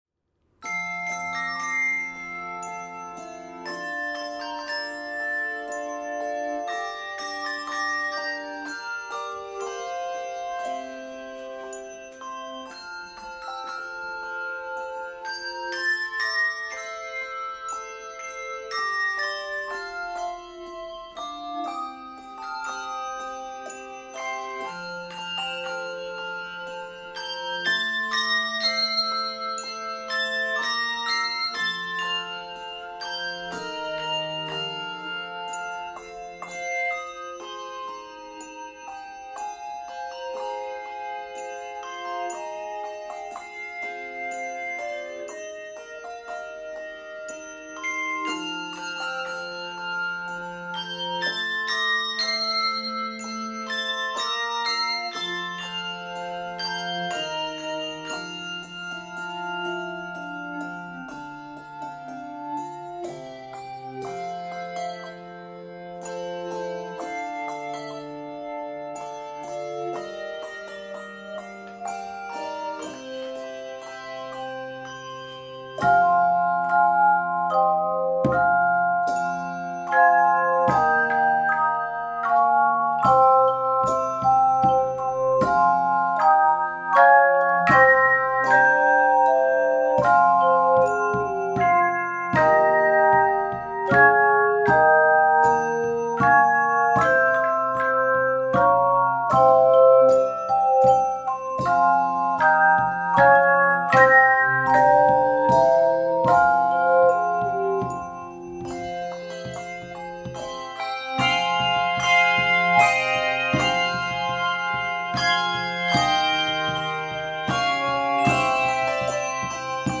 to create a reflective musical mood.
is set in F Major and f minor